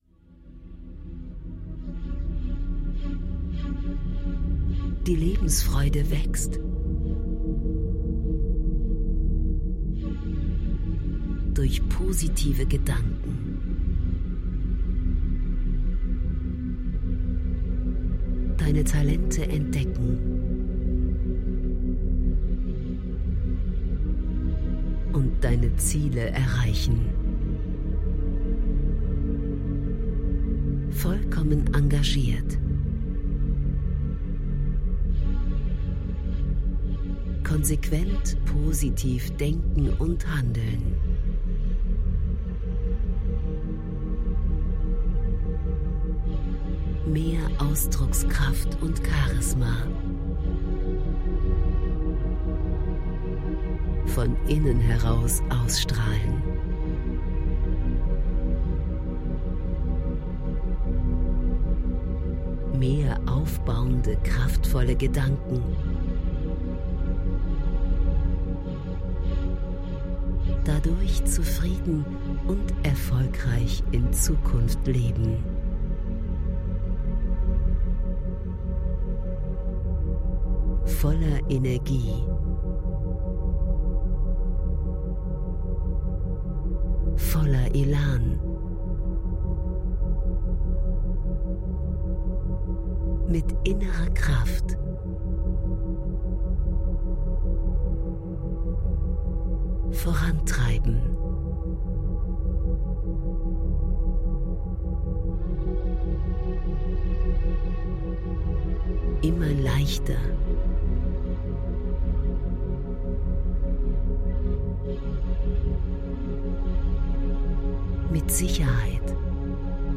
Dieses Hörbuch enthält Anregungen und positive Gedanken für mehr Selbstvertrauen und ein glückliches Lebensgefühl.
Das Besondere an diesem Audiotraining ist die spezielle neurologische Hintergrundmusik Vivaflow Brainwaves.